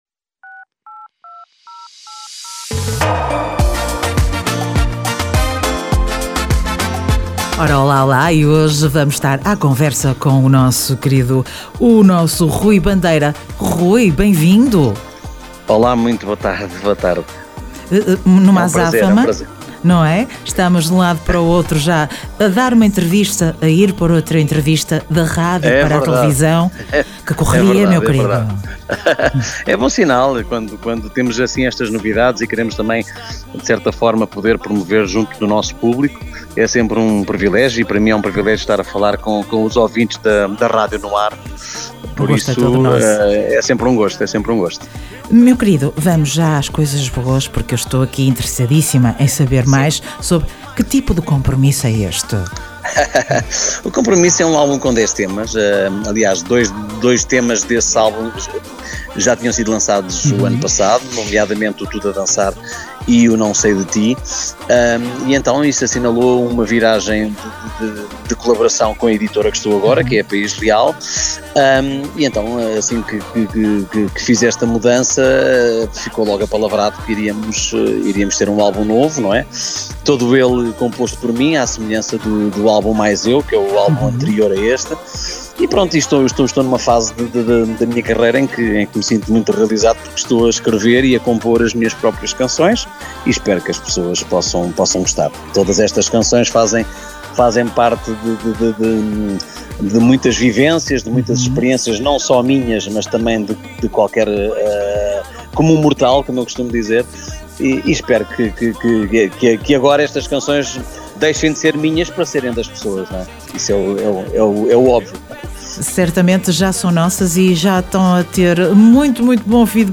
Entrevista Rui Bandeira